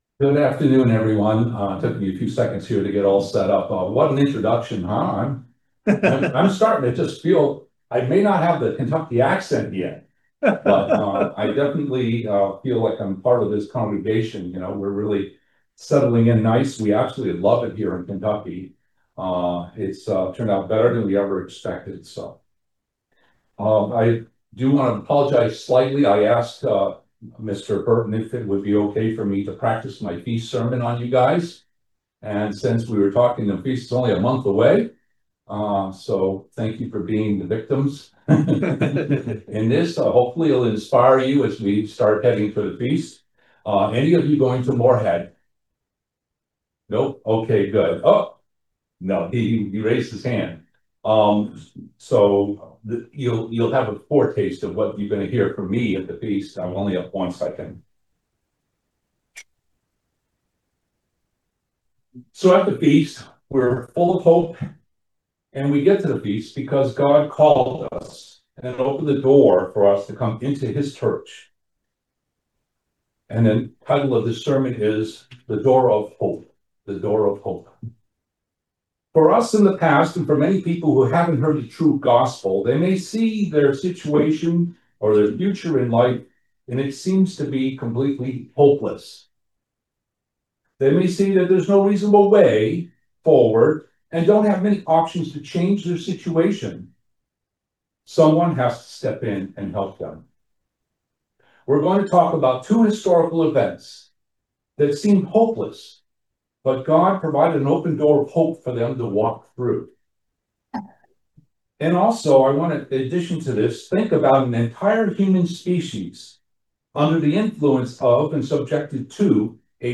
How can we be an open door of hope for others? The answers to these questions and much more in this video sermon.